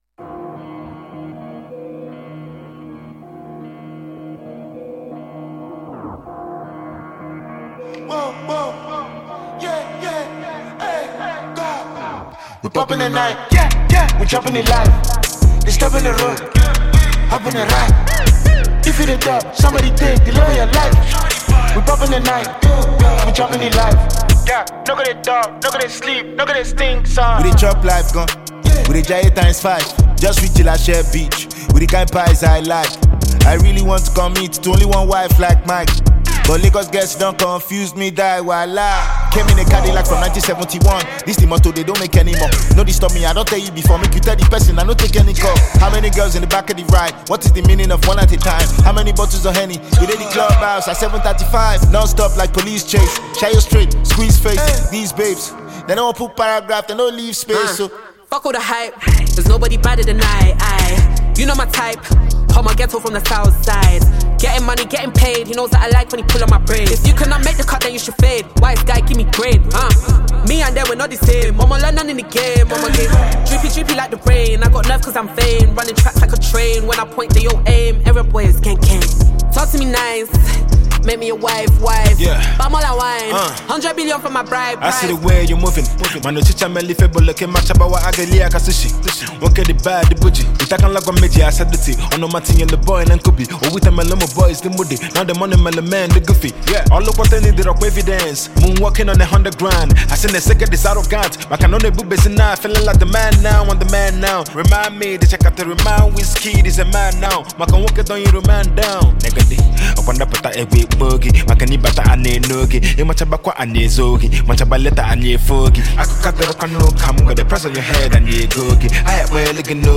African Music Genre: Afrobeats Released